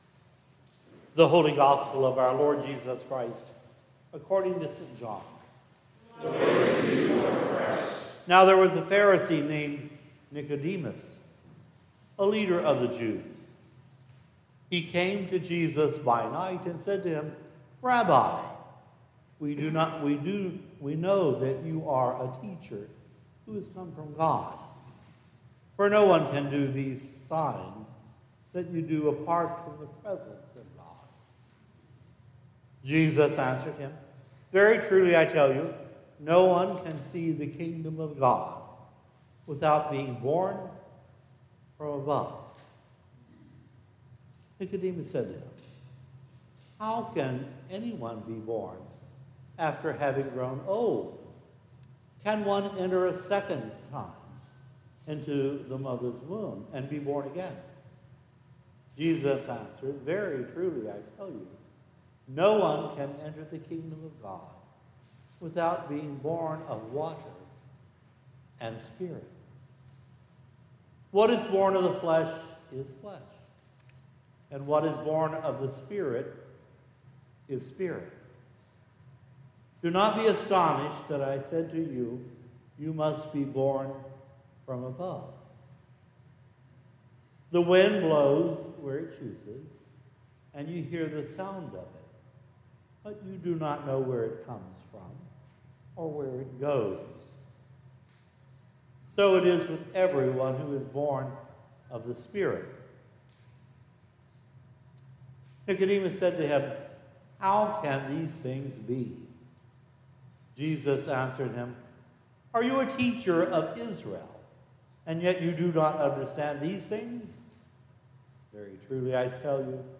March 29, 2026 - Palm Sunday
Latest Sermons & Livestreams